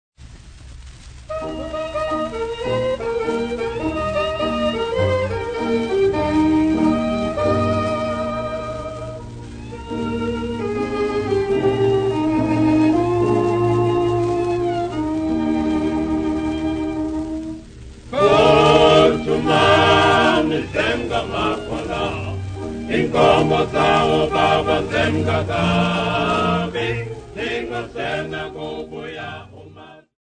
Popular music--Africa
Dance music
Dance music--Caribbean Area
Field recordings
Euro-African party song accomapanied by the jazz band
96000Hz 24Bit Stereo